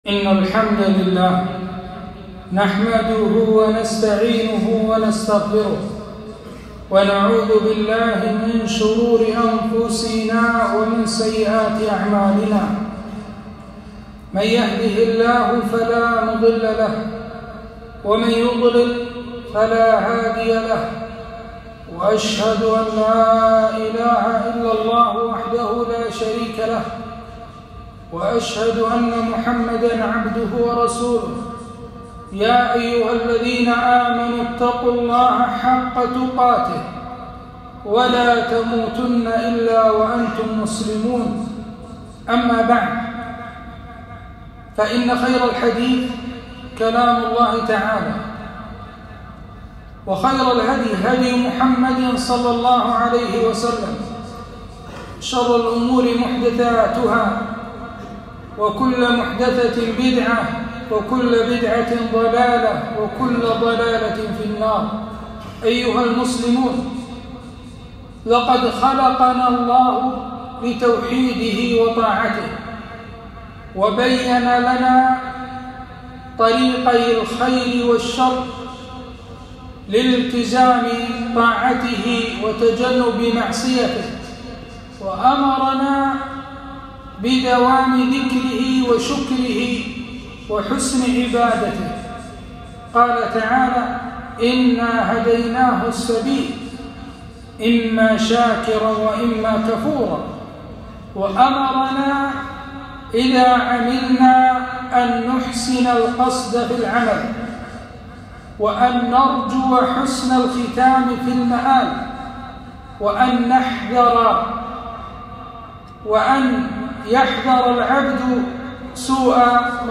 خطبة - إنما الأعمال بالخواتيم